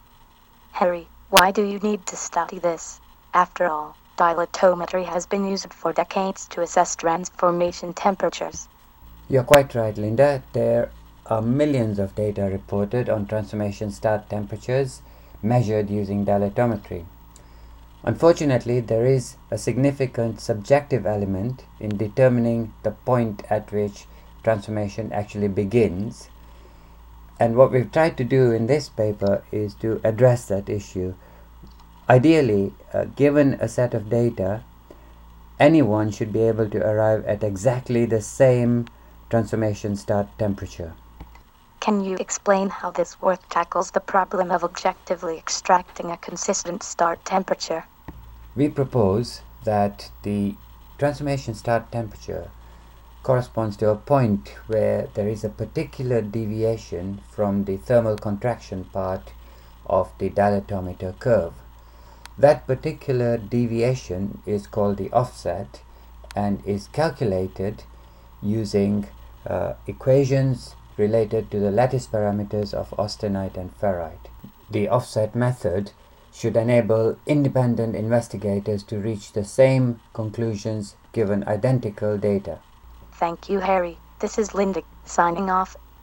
Podcast Short (2 min) audio interview, explaining the contents of the paper.